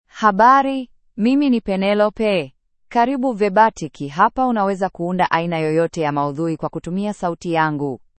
FemaleSwahili (Kenya)
Penelope — Female Swahili AI voice
Penelope is a female AI voice for Swahili (Kenya).
Voice sample
Penelope delivers clear pronunciation with authentic Kenya Swahili intonation, making your content sound professionally produced.